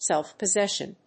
アクセントsélf‐posséssion
音節self-pos･ses･sion発音記号・読み方sèlfpəzéʃ(ə)n